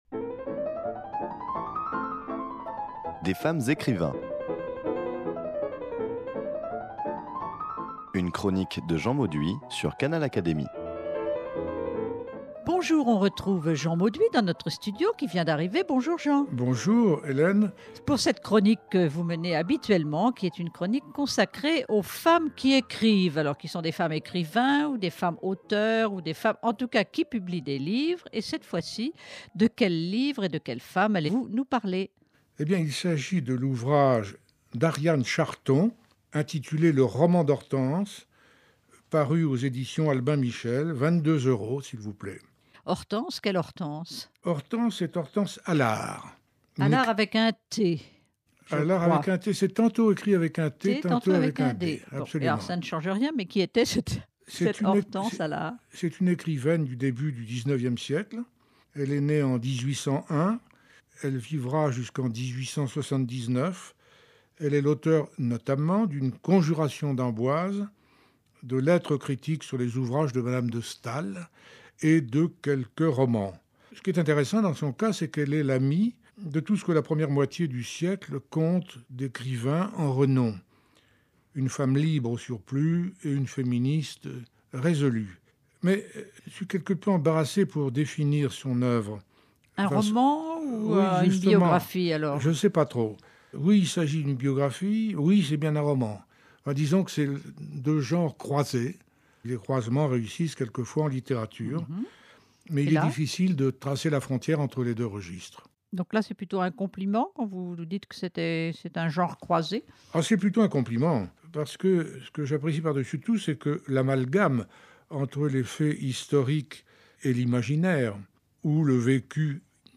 Les femmes écrivains, la chronique